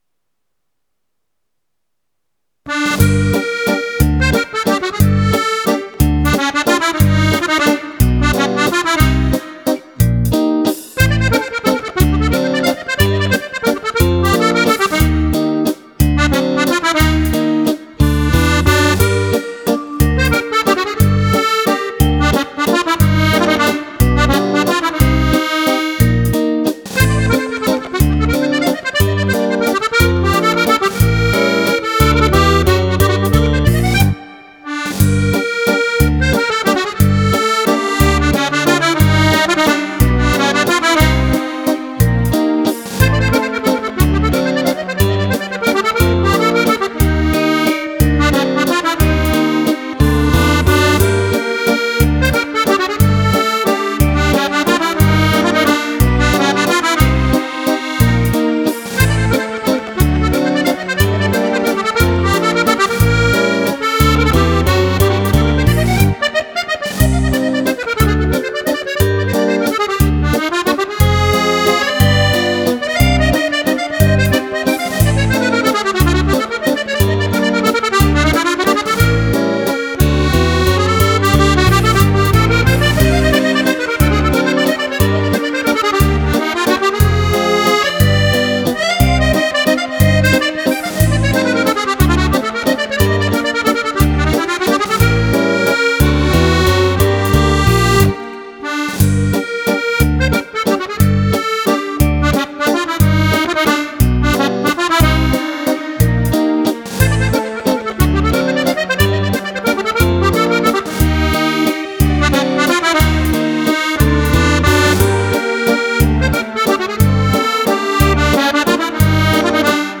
Valzer musette